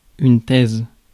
Ääntäminen
Ääntäminen France: IPA: /tɛz/ Haettu sana löytyi näillä lähdekielillä: ranska Käännös Substantiivit 1. теза Suku: f .